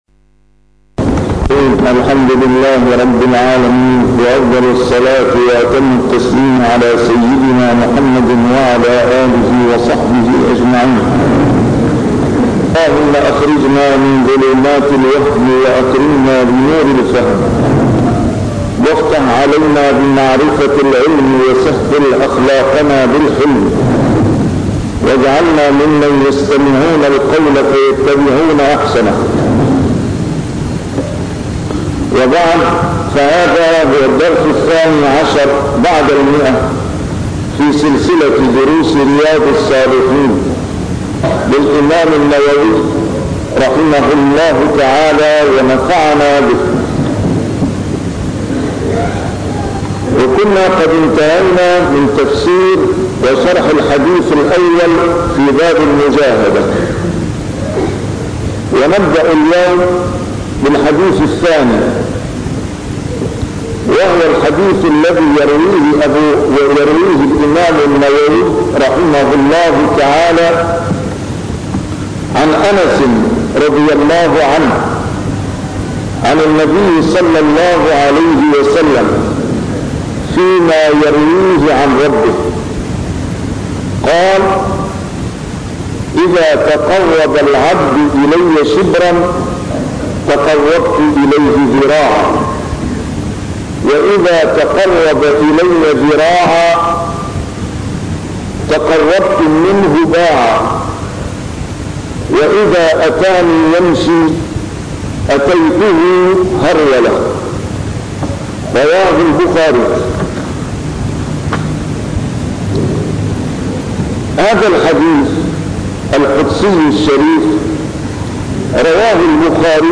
A MARTYR SCHOLAR: IMAM MUHAMMAD SAEED RAMADAN AL-BOUTI - الدروس العلمية - شرح كتاب رياض الصالحين - 112- شرح رياض الصالحين: المجاهدة